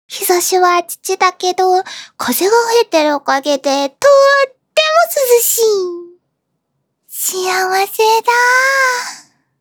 ハコネクト所属メンバーが収録した「夏のおでかけ」をテーマにしたコンセプトボイスを是非お楽しみください！
ボイスサンプル